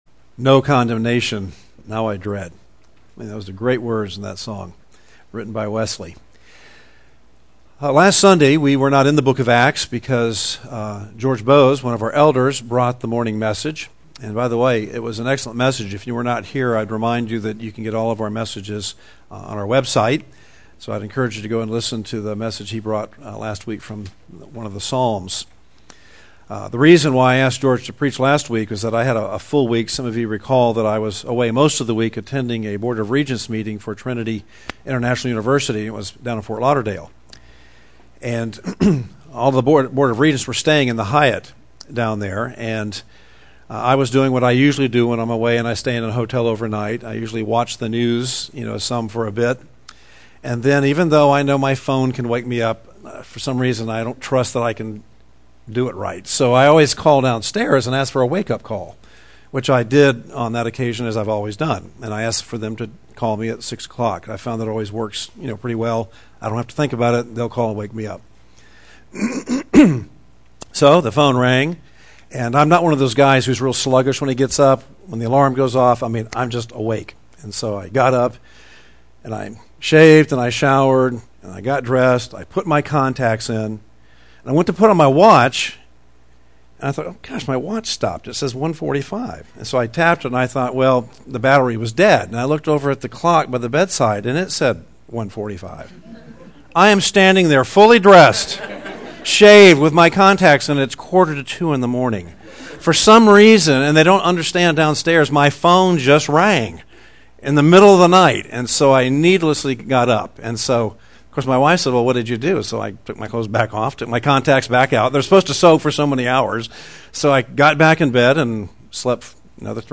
teaches from the series: Acts, in the book of Acts, verses 21:37 - 26:32